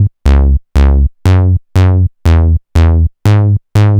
TSNRG2 Off Bass 002.wav